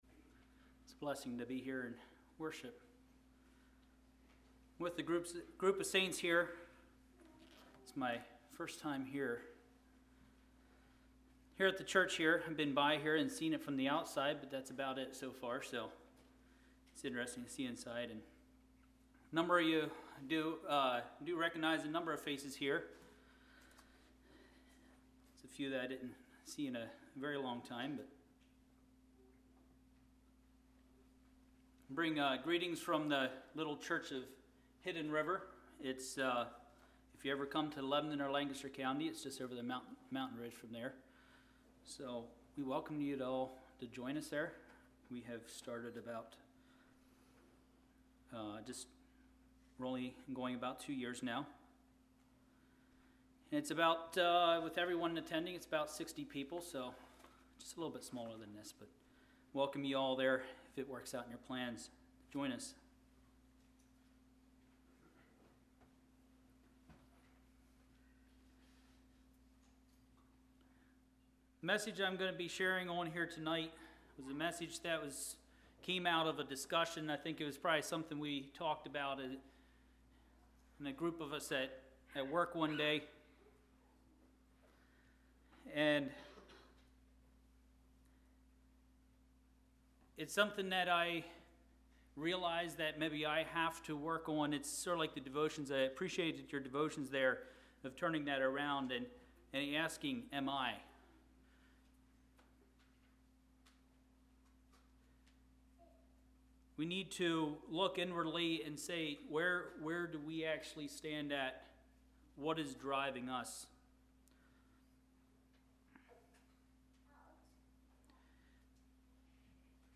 Sermons 08.11.24 Play Now Download to Device Setting Your Sights on Eternity Congregation: Ridge View Speaker